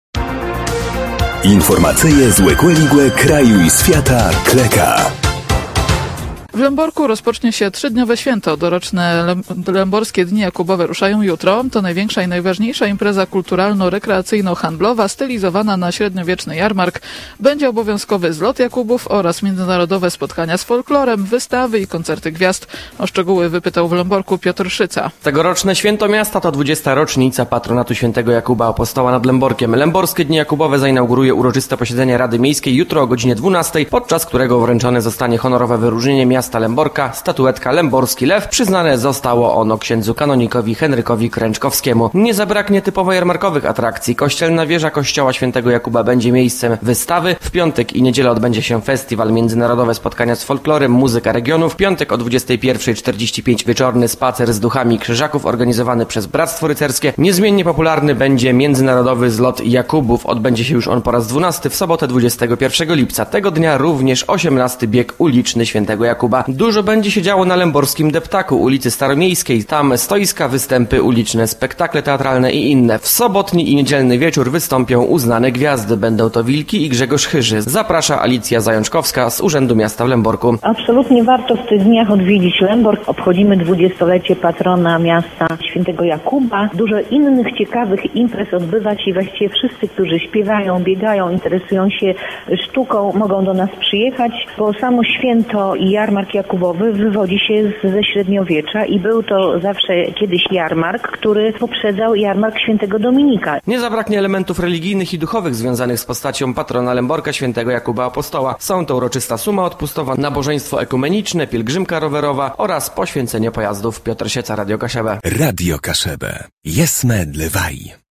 Będzie obowiązkowy Zlot Jakubów oraz Międzynarodowe Spotkania z Folklorem, wystawy i koncerty gwiazd. O szczegóły wypytał w Lęborku